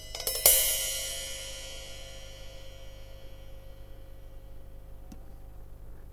吊杆式水龙头
描述：在吊杆上有一些水龙头
标签： 悬臂话筒 吊杆抽头 MIC-抽头
声道立体声